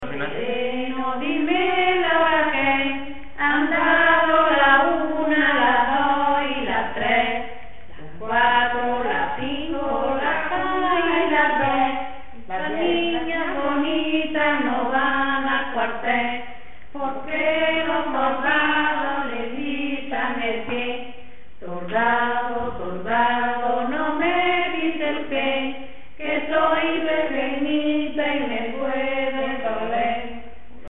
Materia / geográfico / evento: Canciones de comba Icono con lupa
Moraleda de Zafayona (Granada) Icono con lupa
Secciones - Biblioteca de Voces - Cultura oral